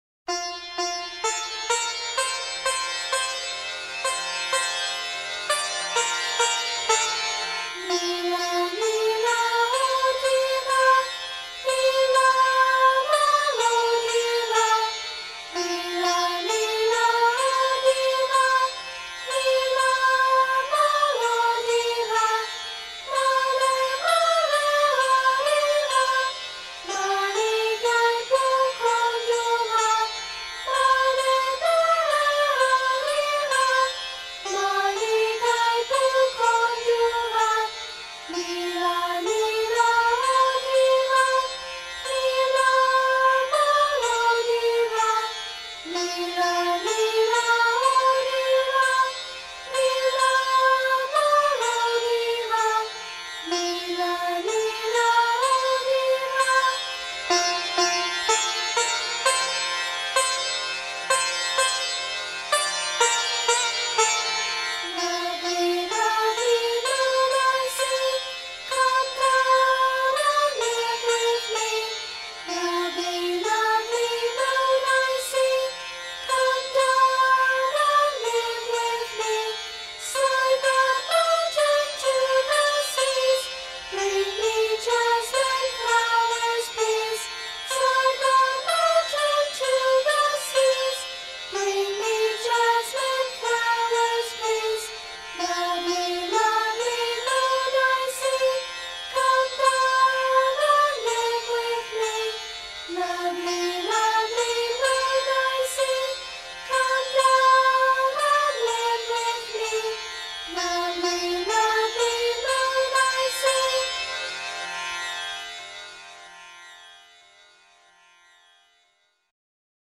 Please use the words in the pdf document above, NOT the English words in the recording: